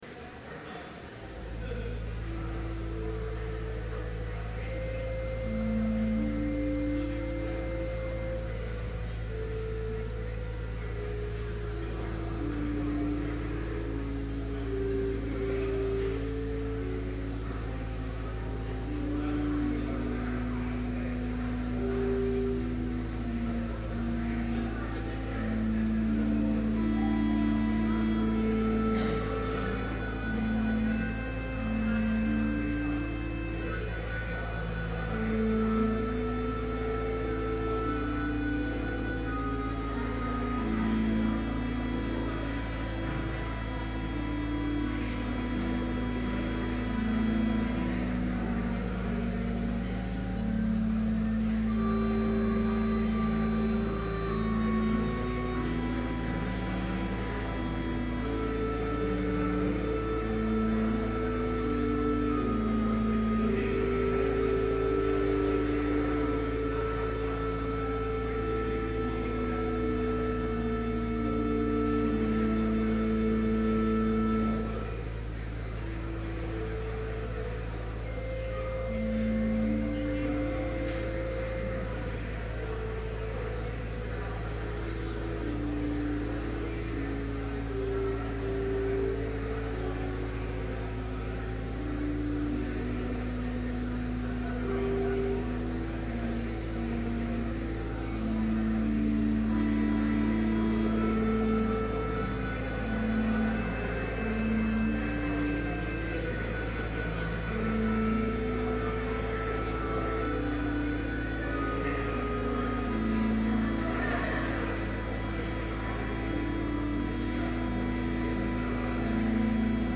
Complete service audio for Chapel - January 30, 2012